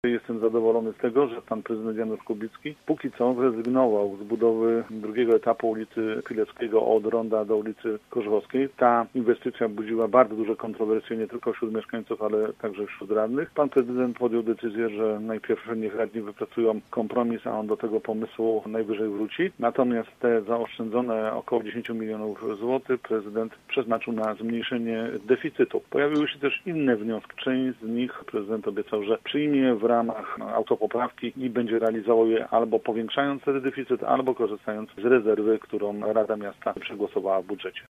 Projekt przyszłorocznego budżetu Zielonej Góry przedstawiał prezydent miasta. Najwięcej, jak mówił Janusz Kubicki, pochłonie oświata: